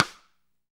SD RI35.wav